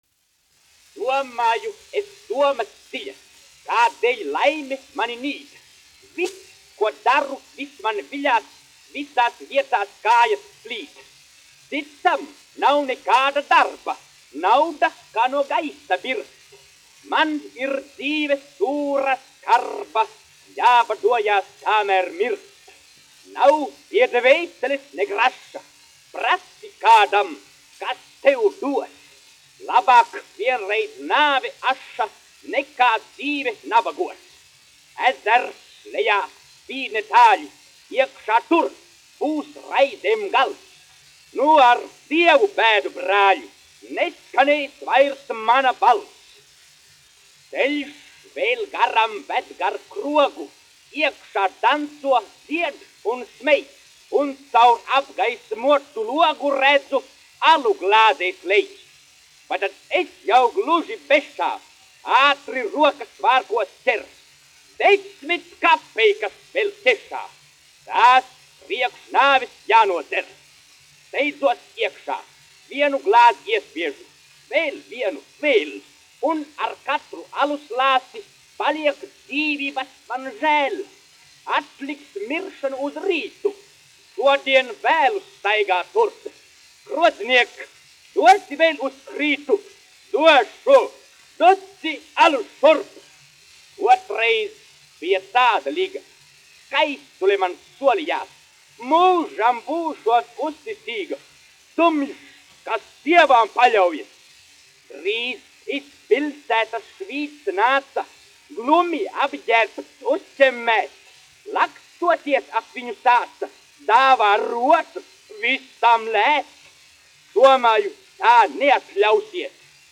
1 skpl. : analogs, 78 apgr/min, mono ; 25 cm
Izpildītājs: Rīgas Jaunā Latviešu teātra aktieris
Skaņuplate